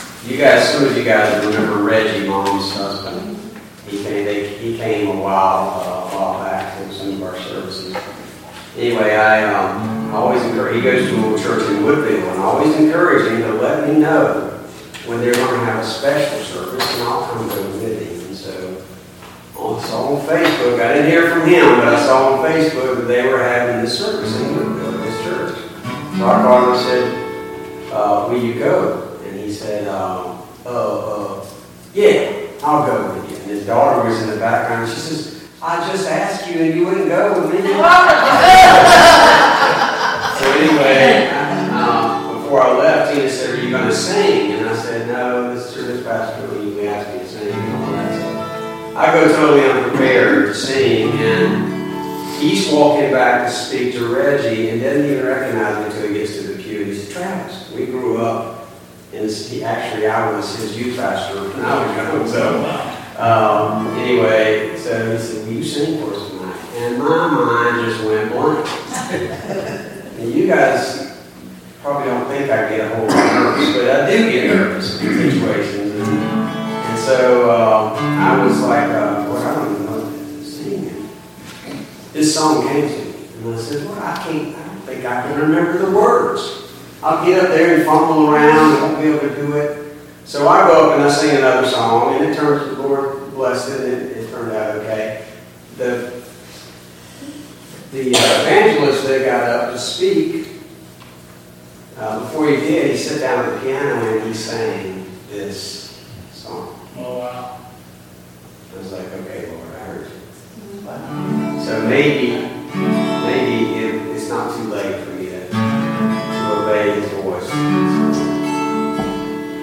I've also included some audio recordings that provide parts of the worship experience that are missing from the online worship service.
Special Music